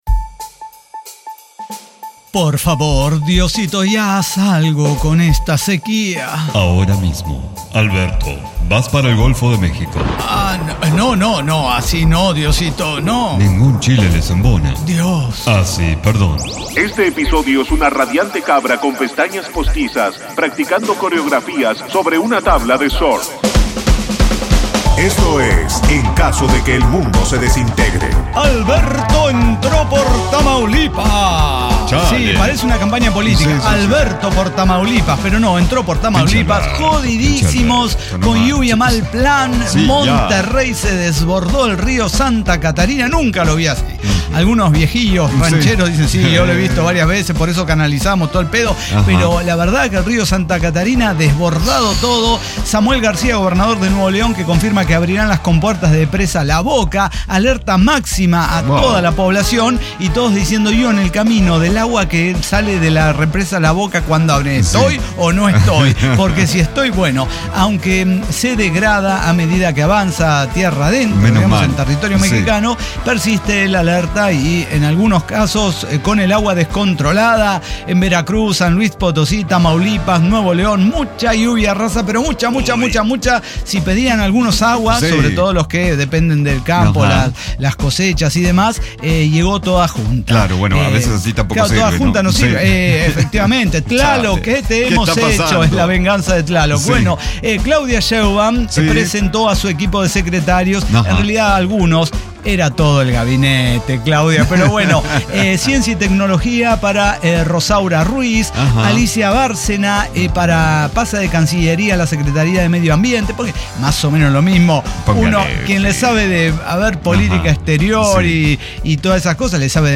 El Cyber Talk Show